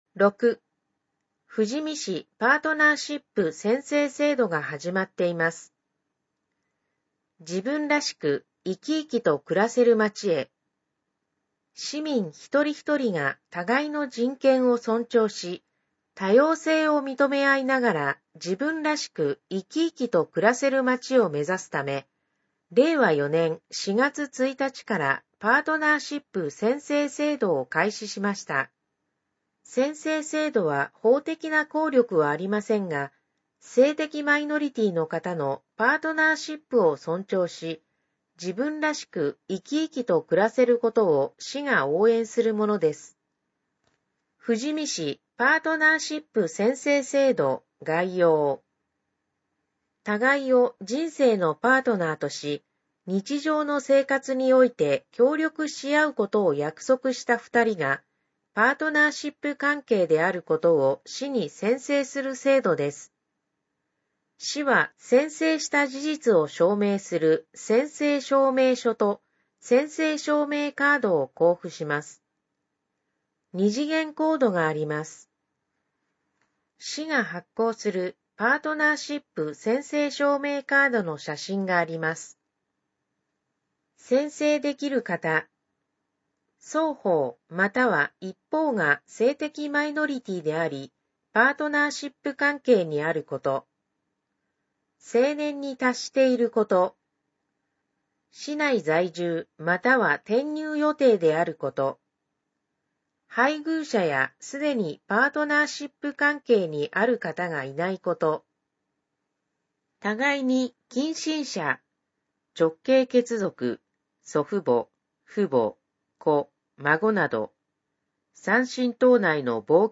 声の広報「富士見」